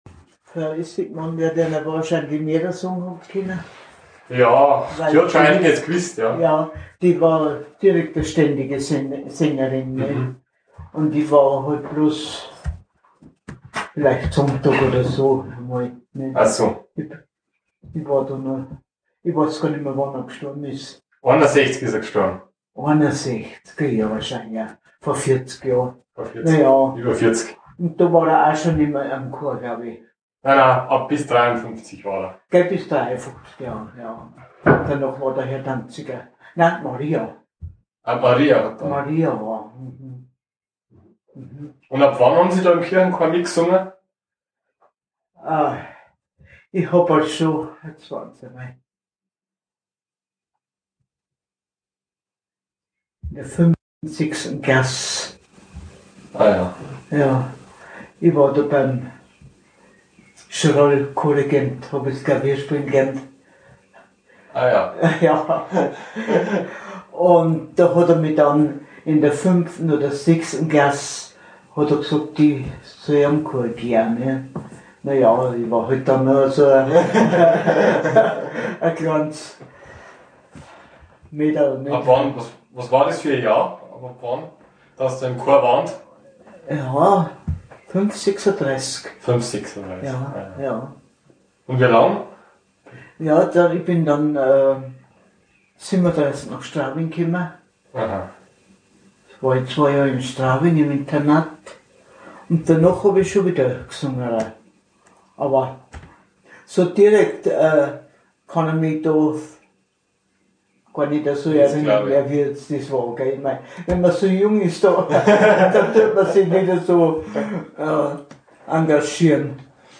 interview.m4a